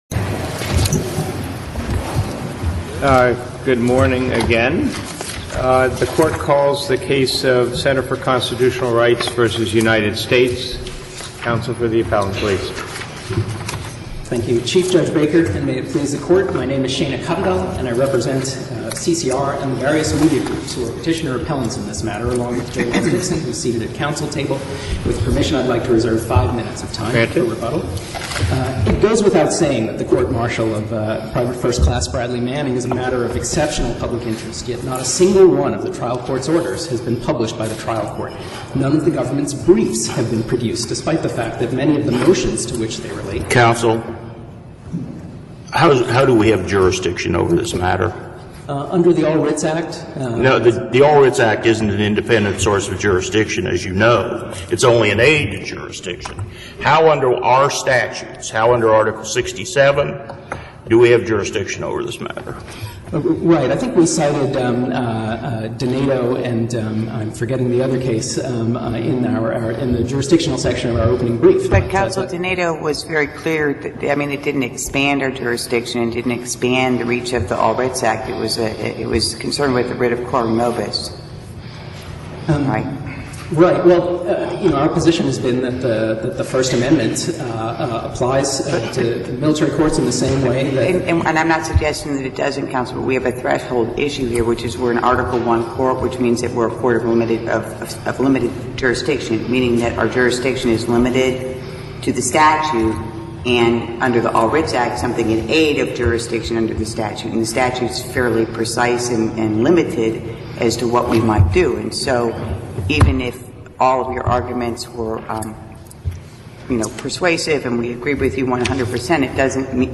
For whatever it’s worth, the audio of the the oral arguments at the Court of Appeals for the Armed Forces in the matter of Center for Constitutional Rights, et al v. United States and Colonel Lind are available here. There were several issues discussed, all of which revolved around CCR’s contention that the first amendment to the US Constitution is being violated by the military’s more restrictive policies customs and statutory limitations.